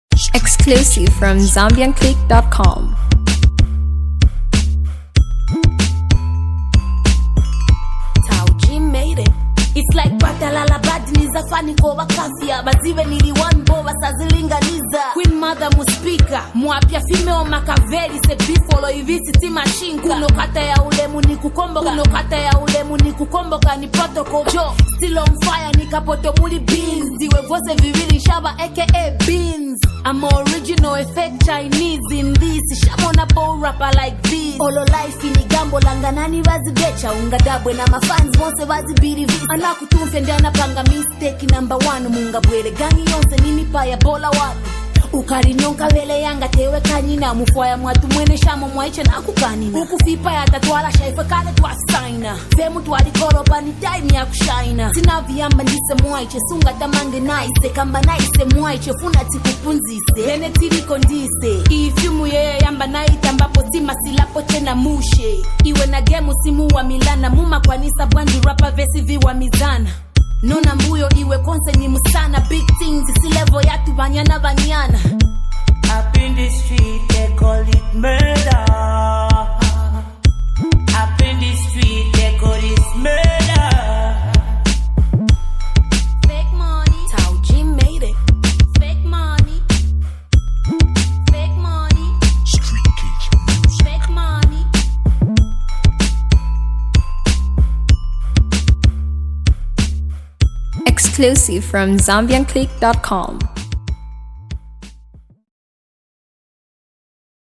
Blending smooth production with honest storytelling